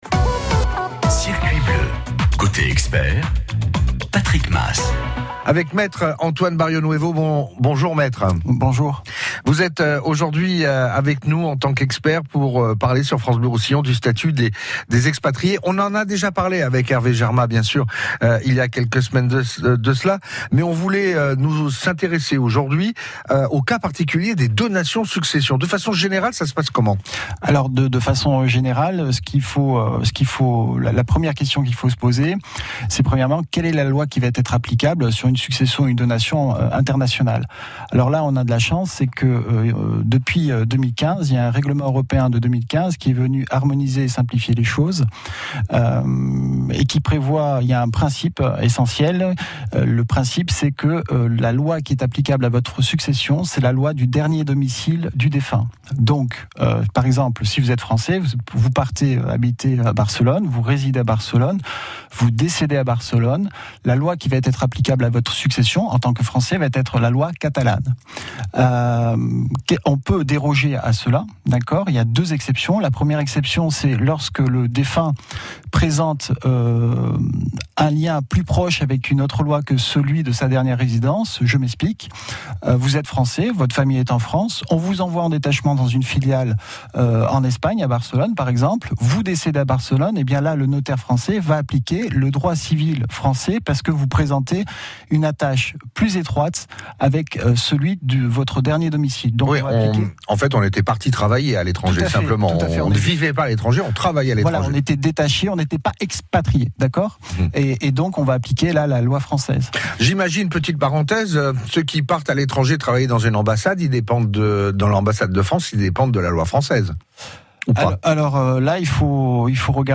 Extrait radio sur le régime des donations et successions en Catalogne pour les expatriés et propriétaires français